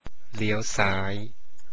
리여우 싸이 - 왼쪽으로 도세요 เลี้ยวซ้าย